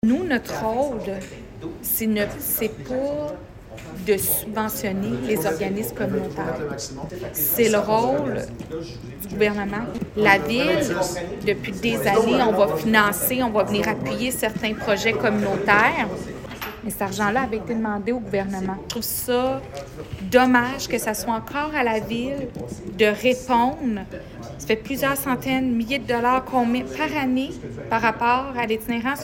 Nouvelles
Pour la mairesse de Granby, Julie Bourdon, sa municipalité n’a pas le choix de prendre le taureau par les cornes et de combler elle-même le manque à gagner.